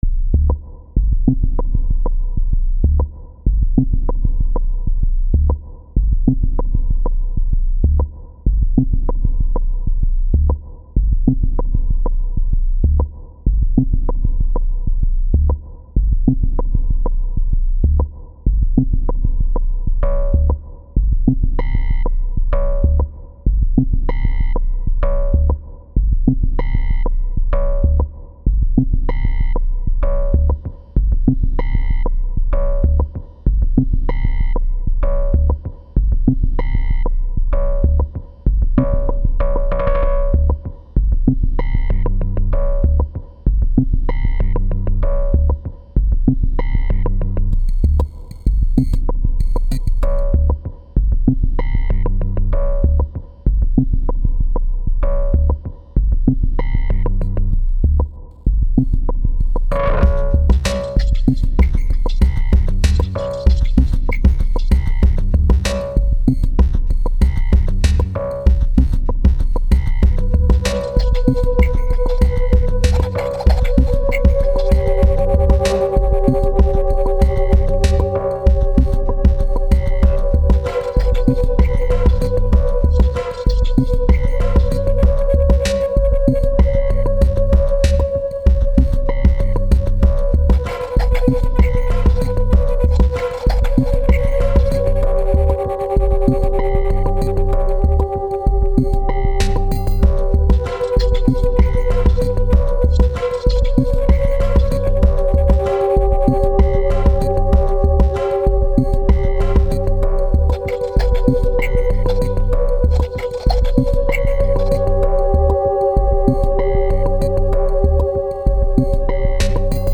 downbeat style